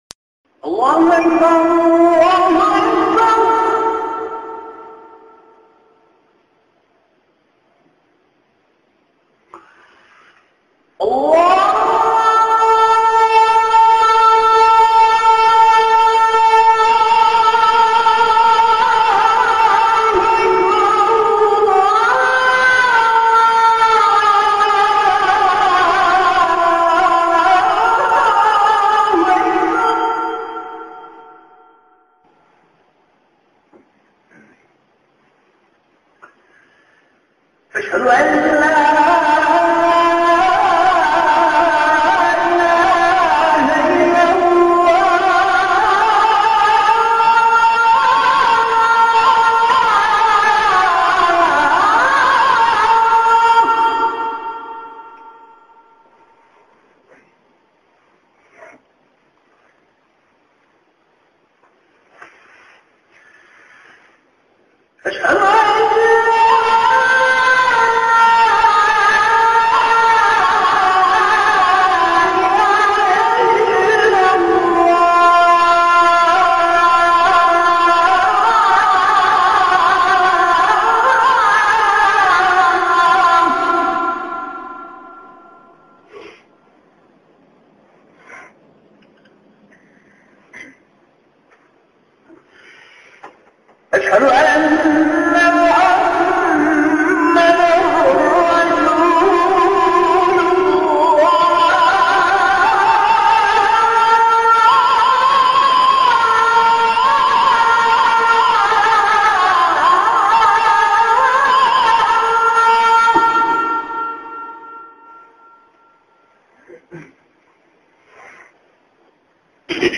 Ezan Dinle - Kuran-ı Kerim Meali
ezan.mp3